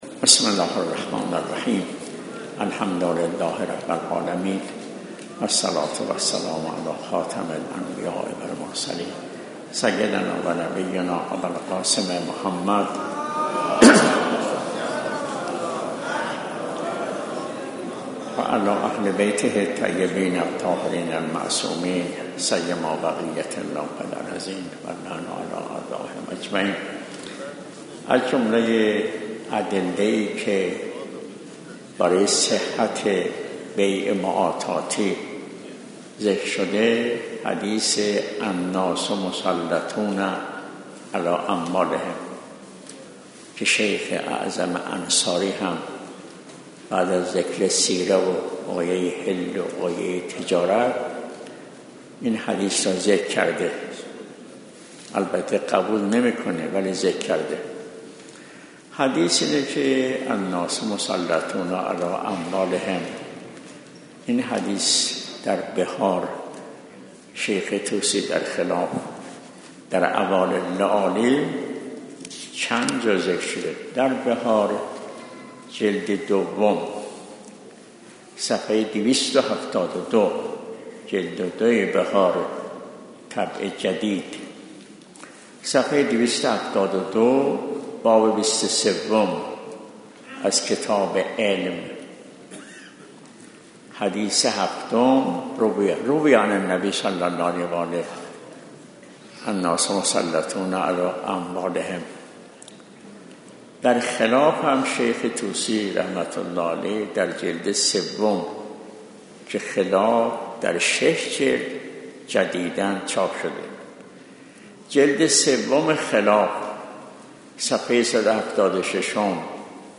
آيت الله نوري همداني - بيع | مرجع دانلود دروس صوتی حوزه علمیه دفتر تبلیغات اسلامی قم- بیان